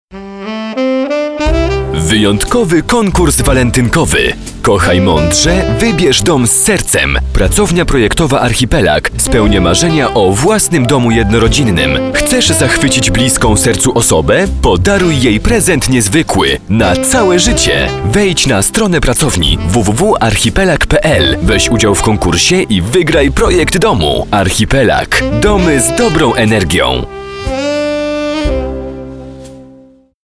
Professioneller polnischer Sprecher für TV/Rundfunk/Industrie.
Sprechprobe: Werbung (Muttersprache):